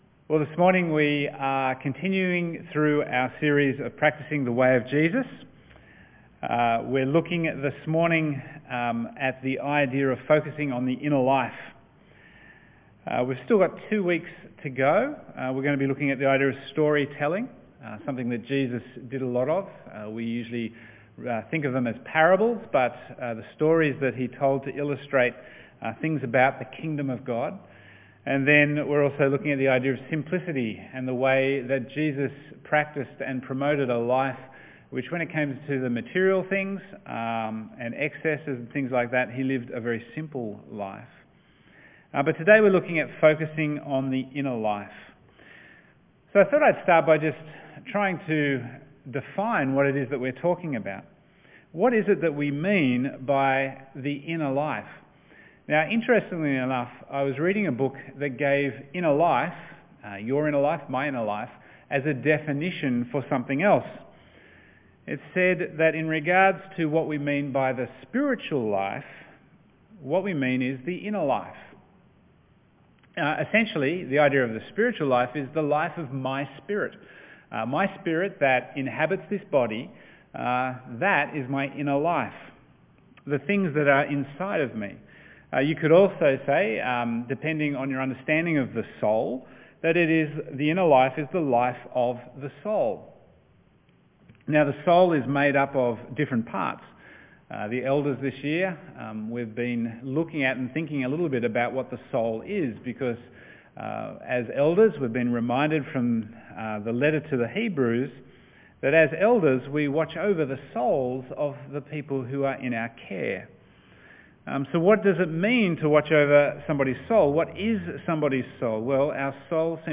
Bible Text: Psalm 92:12-15, Luke 6:43-45 | Preacher